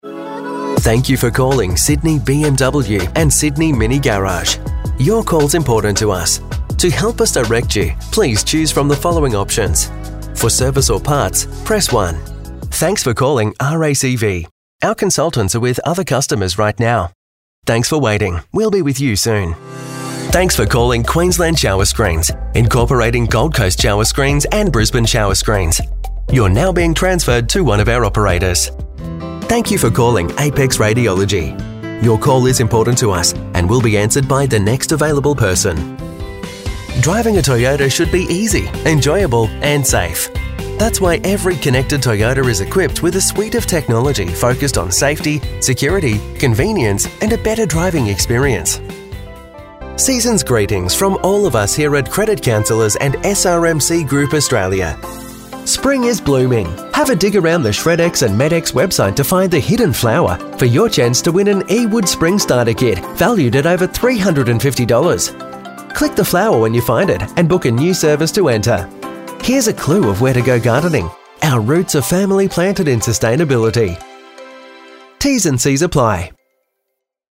IVR
Modulated, clear Australian accent.
Mic: Sennheiser MKH-416.
Location: Custom vocal booth .
English (Australian)
Baritone